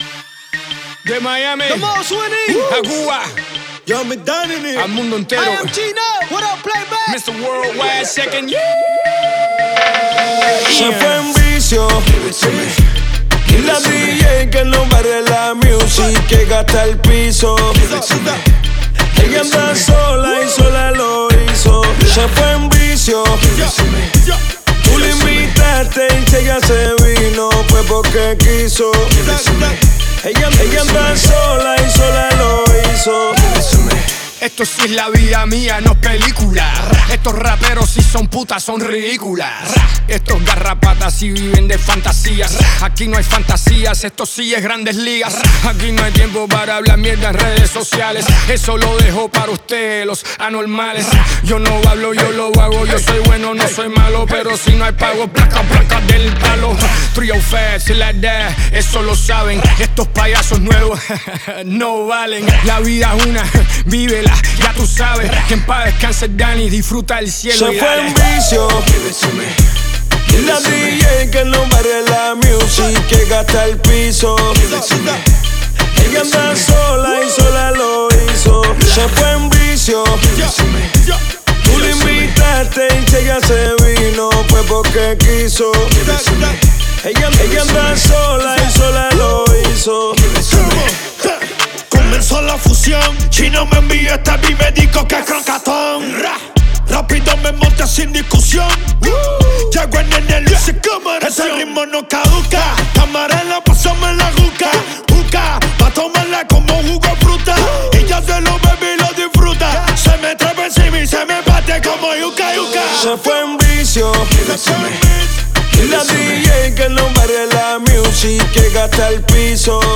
зажигательный трек в жанре реггетон